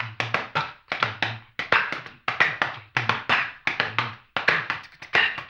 HAMBONE 06.wav